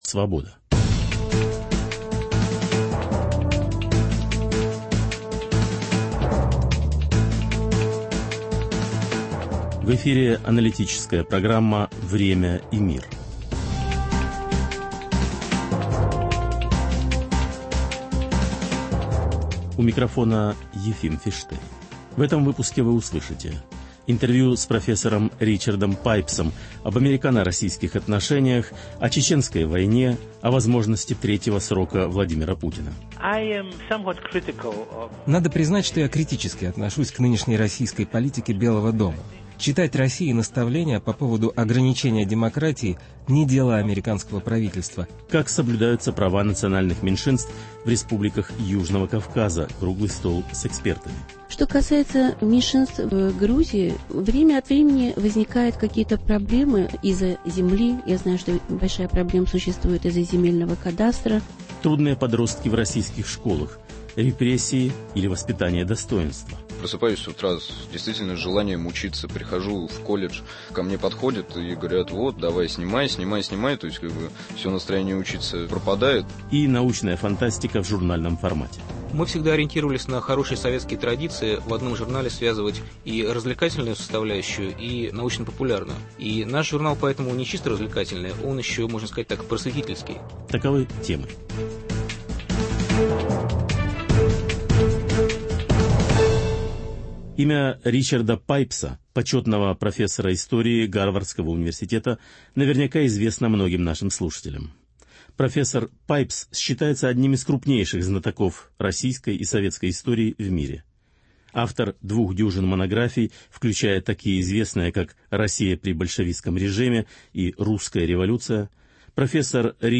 Интервью с профессором Ричардом Пайпсом о состоянии американо-российских отношений.